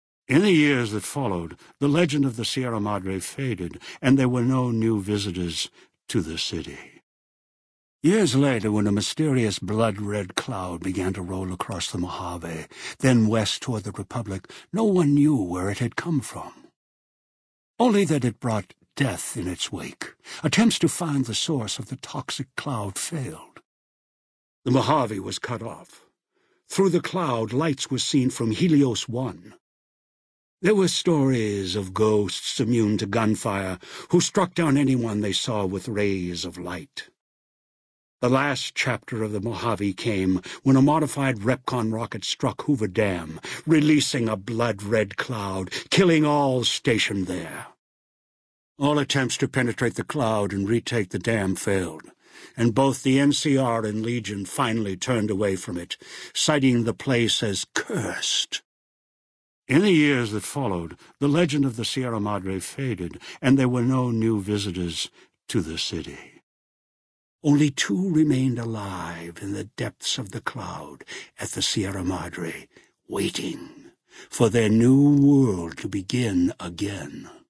Category:Dead Money endgame narrations Du kannst diese Datei nicht überschreiben. Dateiverwendung Die folgende Seite verwendet diese Datei: Enden (Dead Money) Metadaten Diese Datei enthält weitere Informationen, die in der Regel von der Digitalkamera oder dem verwendeten Scanner stammen.